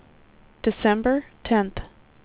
WindowsXP / enduser / speech / tts / prompts / voices / sw / pcm8k / date_28.wav